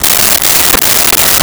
Saw Wood 02
Saw Wood 02.wav